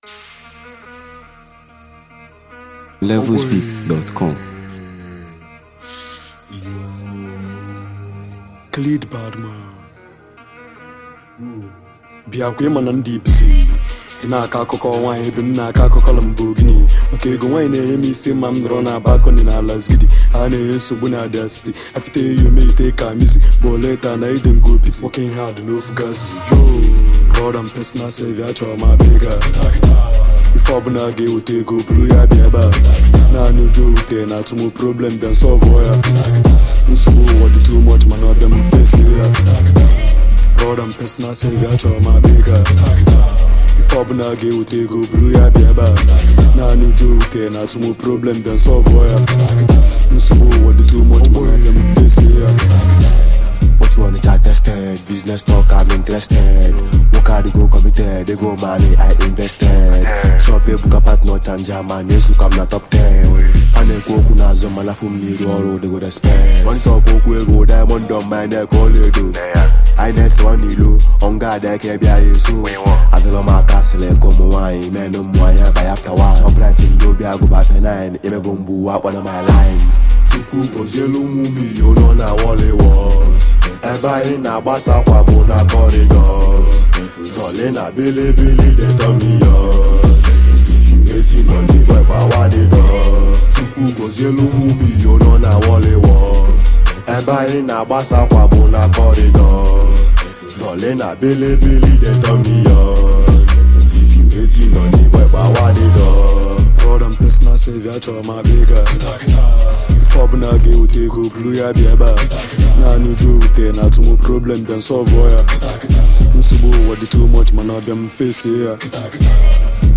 electrifying trap hit